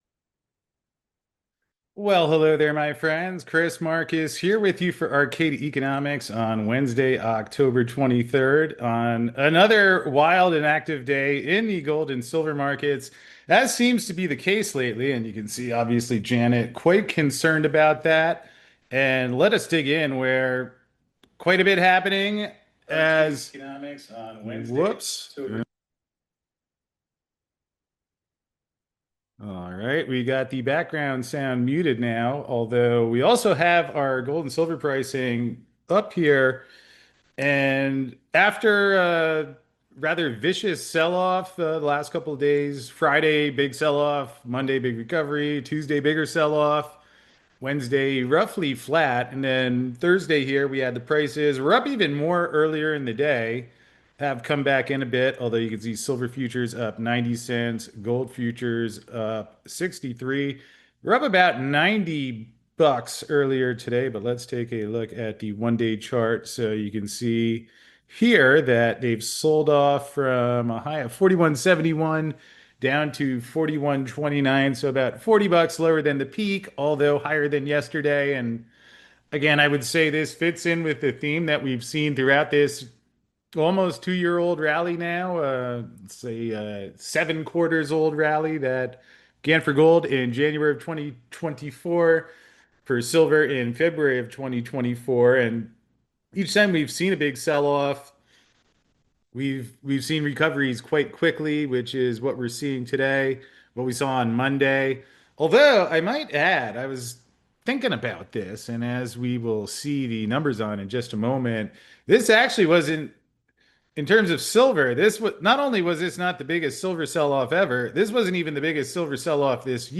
So to find out more, join us for this live call!